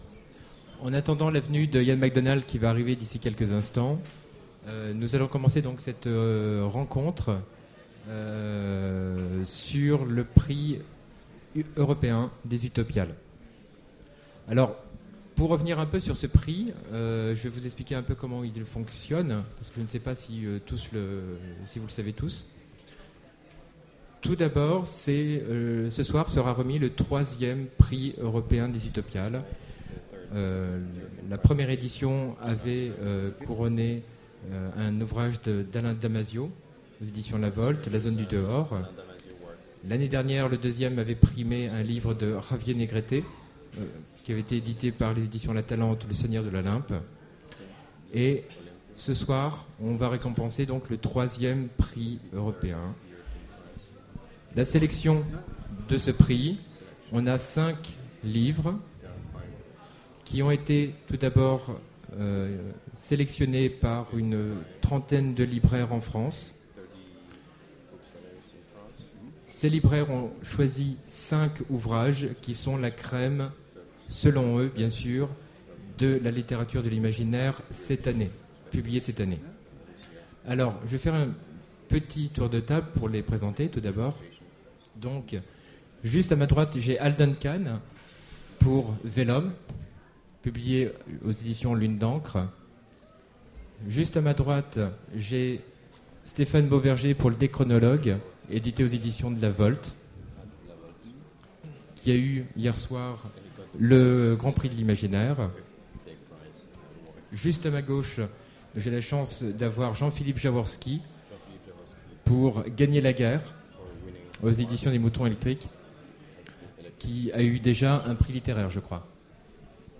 Voici l'enregistrement de la conférence :Autour des cinq nominés du Prix Utopiales Européen aux Utopiales 2009.